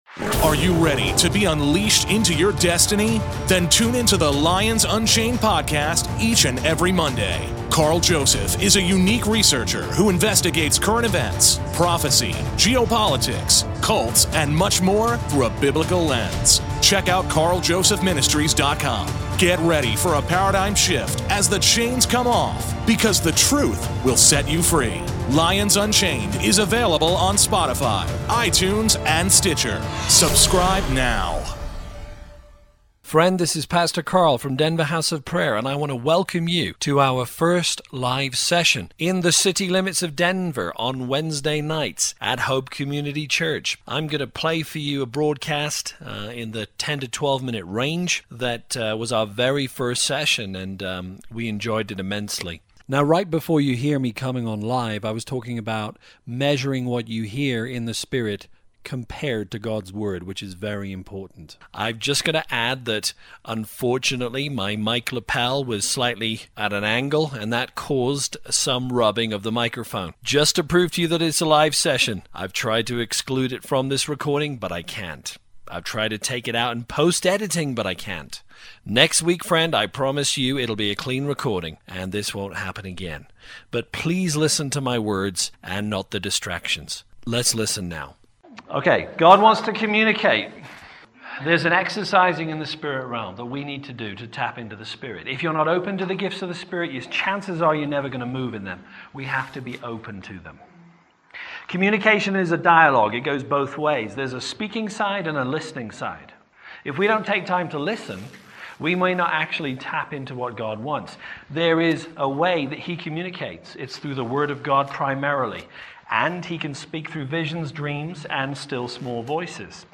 God Wants to Communicate: Part 1 (LIVE)